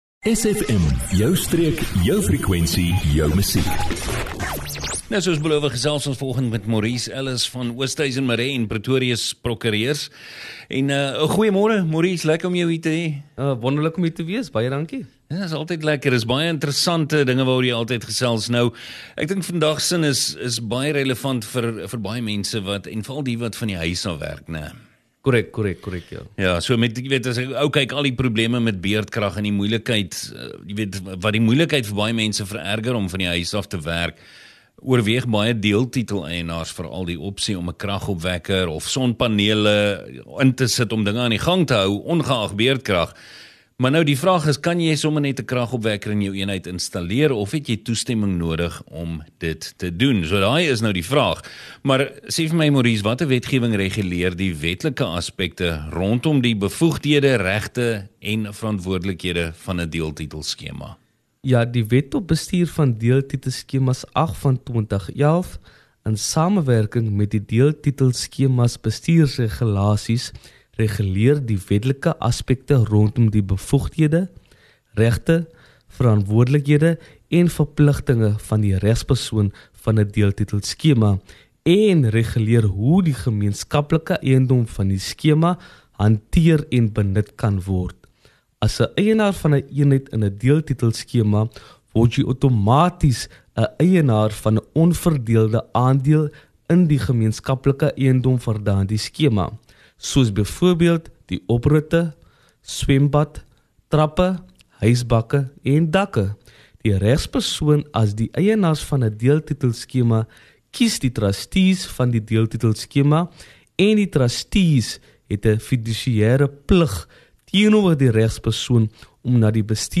SFM Allegaartjie van onderhoude